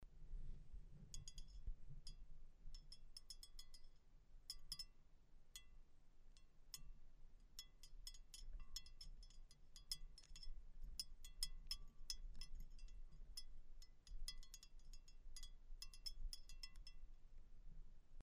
Sound recordings of the original pellet bells and bells from the Avar period kept in the collection of the Great Migration Period of the Hungarian national Museum Budapest.
Original sound of bell Regöly-Kapuvár grave C 0.28 MB
bell_Regoely_Kapuvar_Bozot_dueloe_Grave_C.MP3